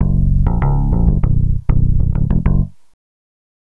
Swinging 60s 5 Bass-F#.wav